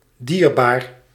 Ääntäminen
IPA: /ˈdirbar/